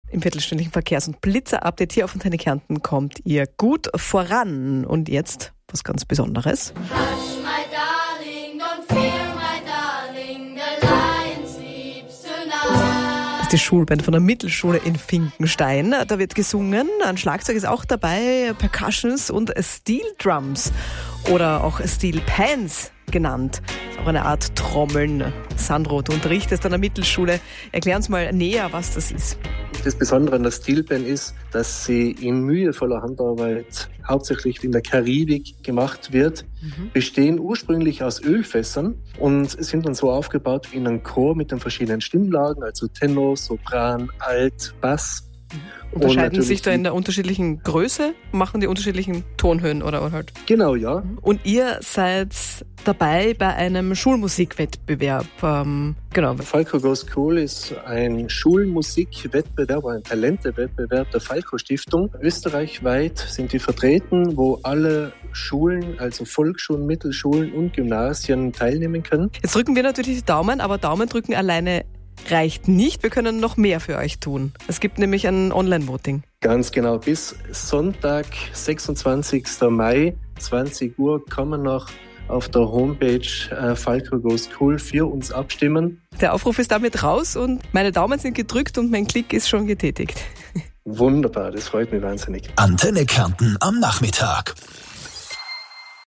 Antenne Kärnten Radiointerview